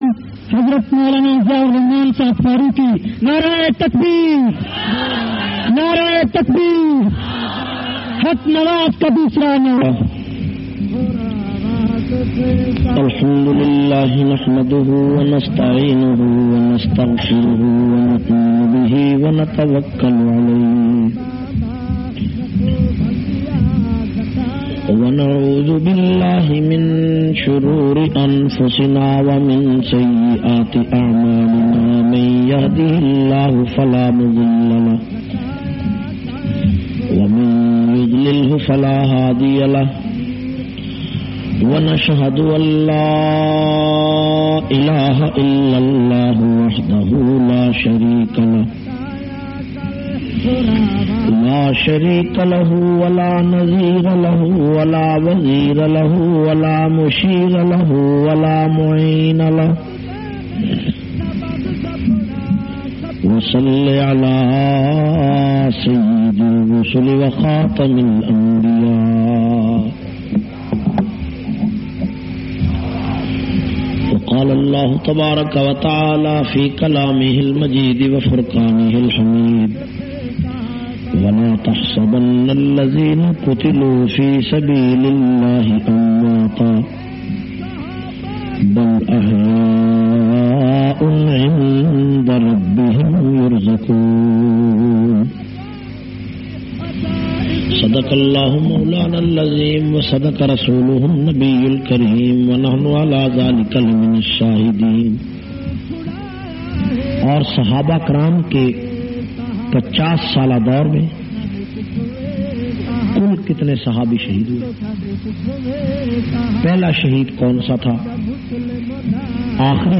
462- Shahadat Conference Faridabad.mp3